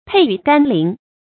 沛雨甘霖 pèi yǔ gān lín
沛雨甘霖发音